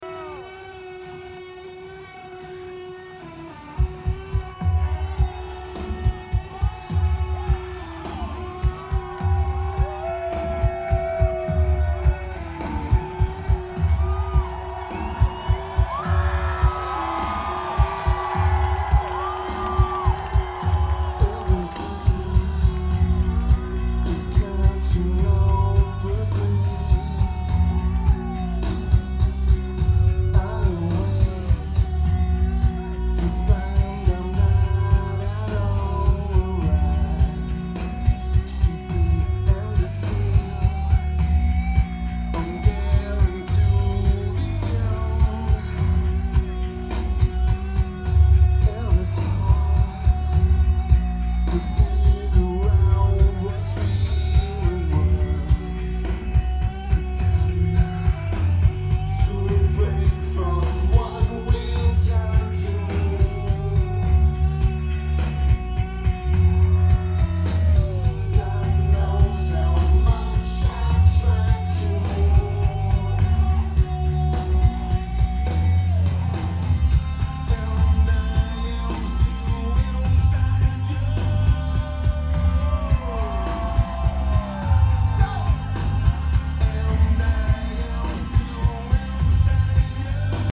Bonnaroo Music Festival
Lineage: Audio - AUD (CA-11 + CA-9100 + Iriver iHP-120)